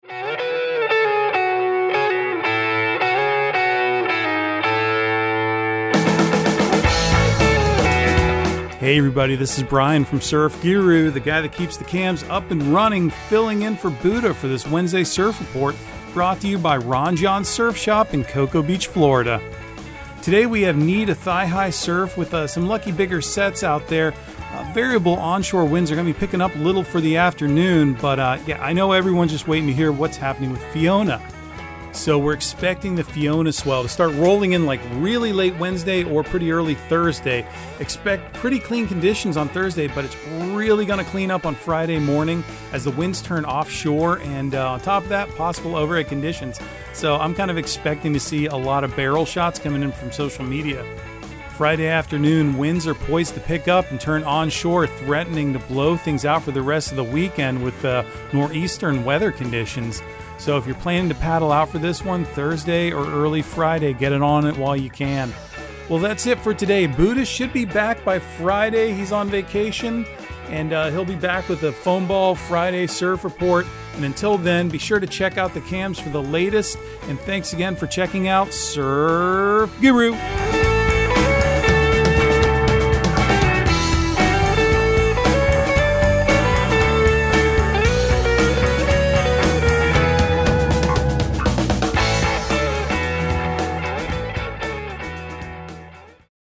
Surf Guru Surf Report and Forecast 09/21/2022 Audio surf report and surf forecast on September 21 for Central Florida and the Southeast.